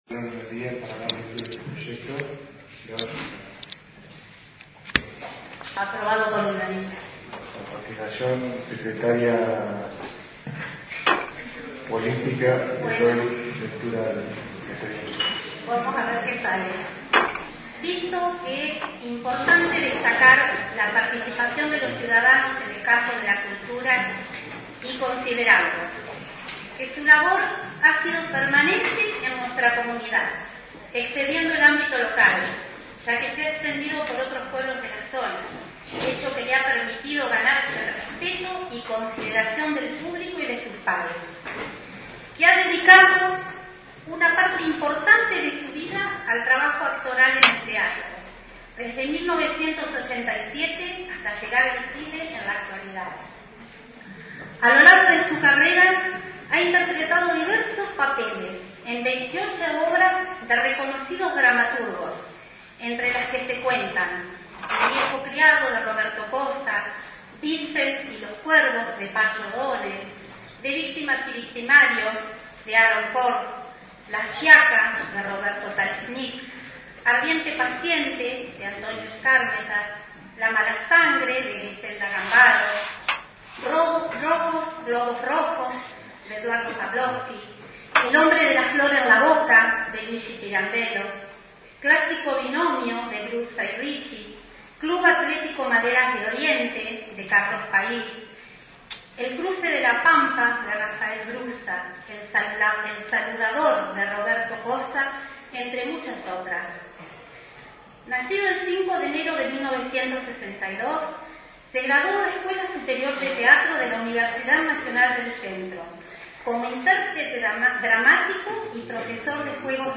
Concideraciones del Decreto del Concejo y palabras alusivas de los Concejales Oscar Ibañez, Gustavo García y José G. Erreca